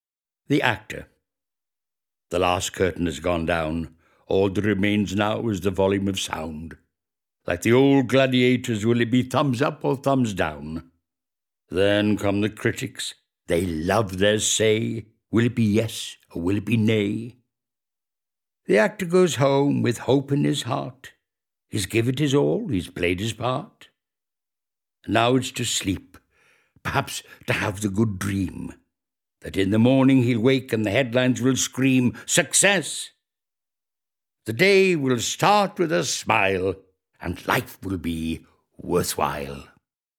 Click here to play poem read by Victor Spinetti